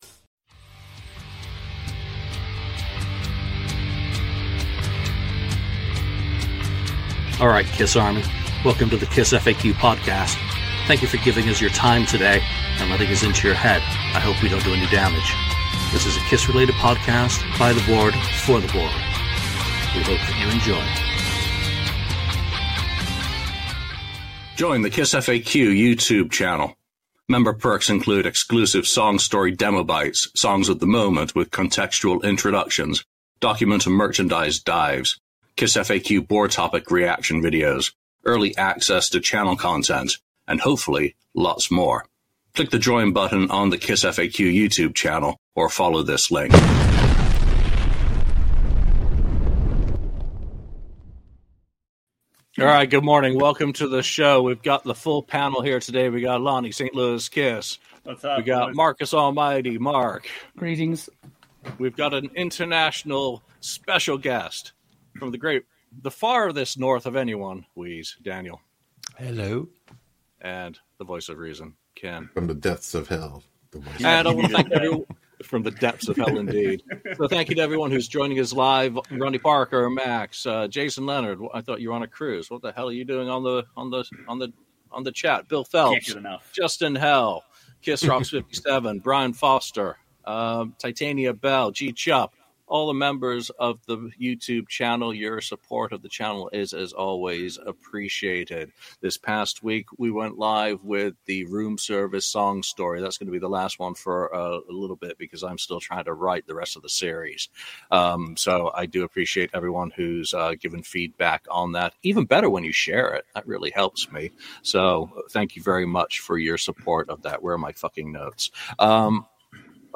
The KissFAQ Podcast is a weekly chat show featuring members of the KissFAQ message board and other guests discussing a wide variety of KISS-related topics.